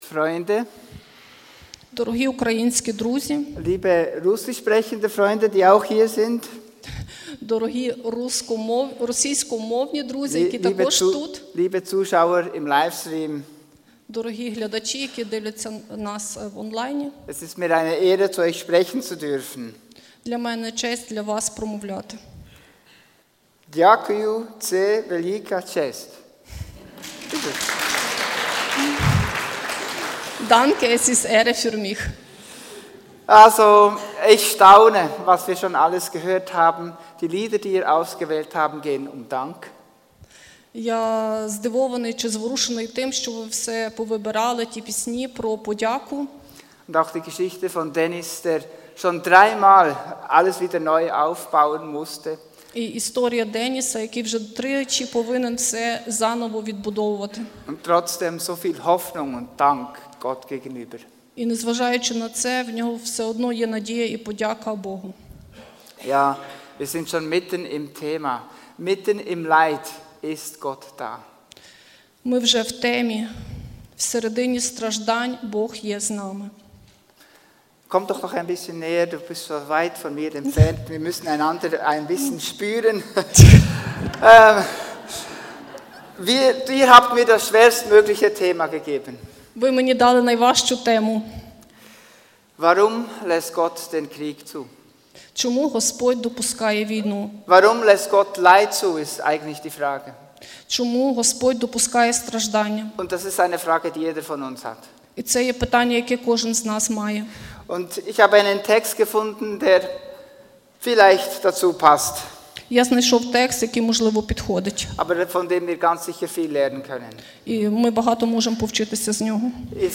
Podcast FEG Langenthal - Ukrainischer Gottesdienst - Warum lässt Gott Krieg zu?